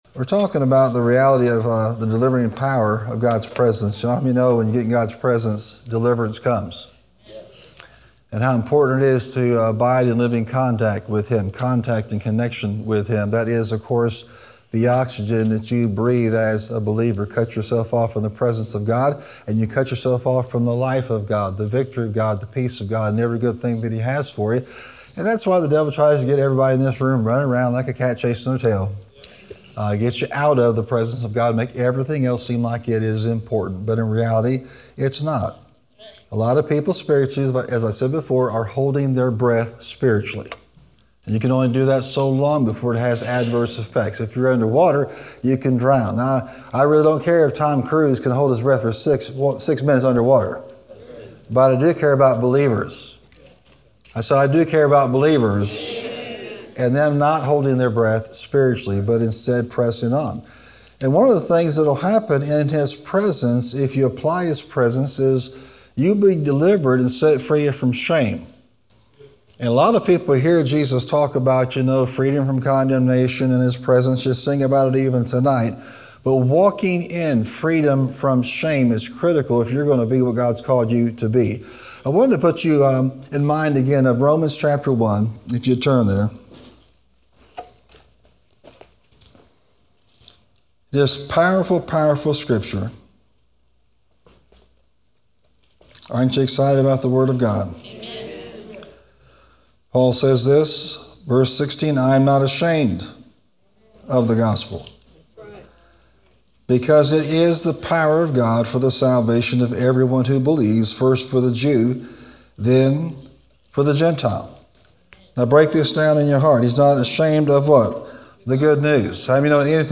Practical hope-building teaching from God's Word